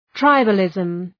Προφορά
{‘traıbə,lızm}